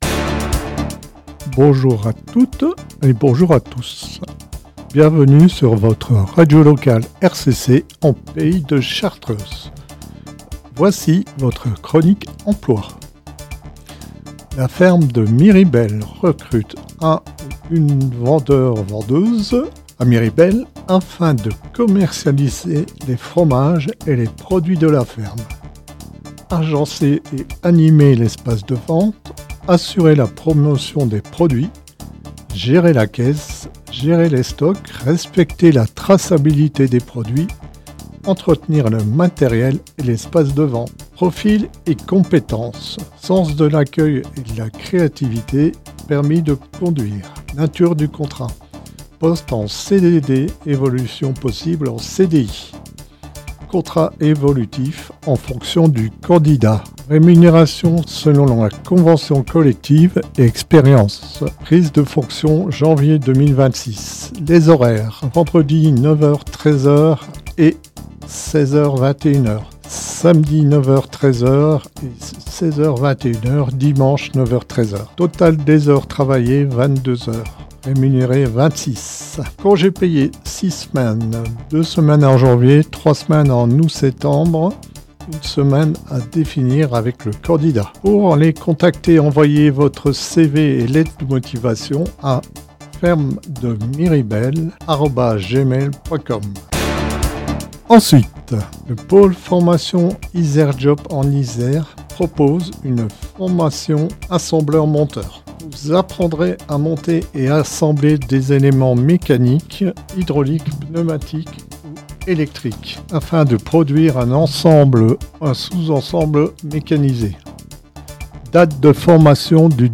Chaque semaine, le mercredi, RCC diffuse la chronique emploi dans la matinale « Bonjour la chartreuse » de 6h à 10h et dans le Chartreuse infos 16h-18h vers 40′ de chaque heure.